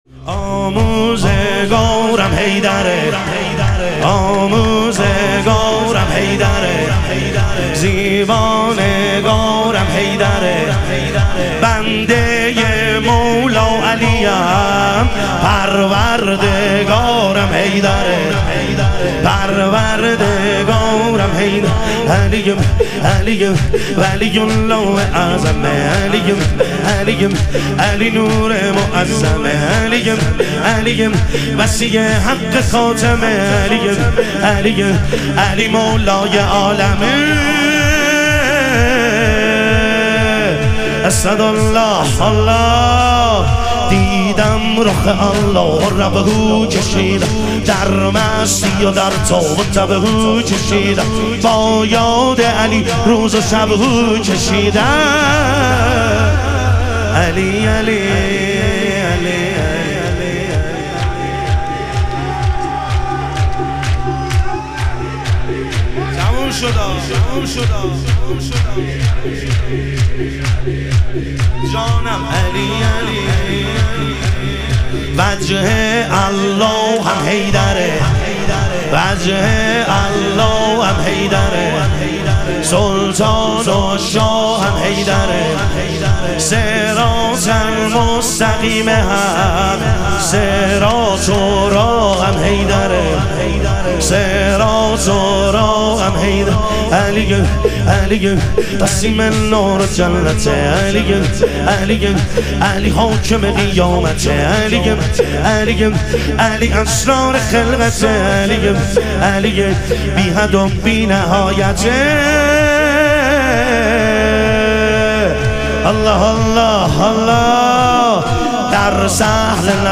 شهادت حضرت ام البنین علیها سلام - شور